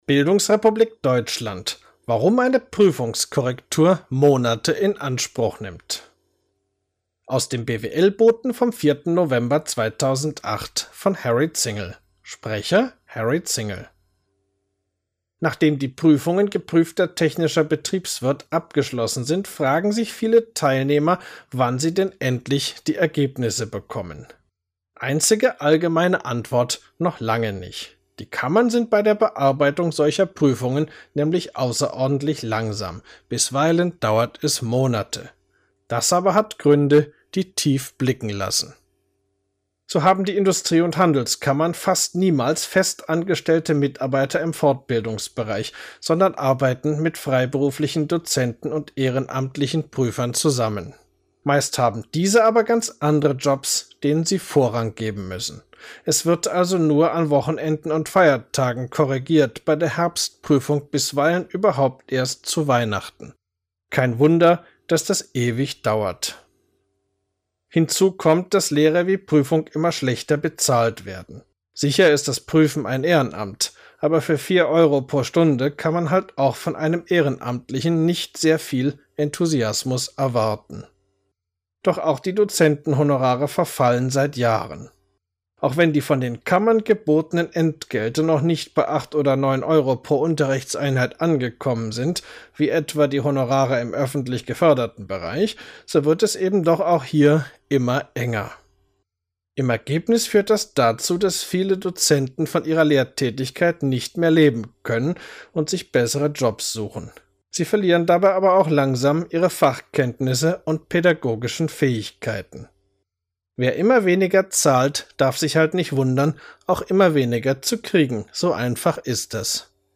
Artikel als MP3 anhören, gesprochen vom Autor: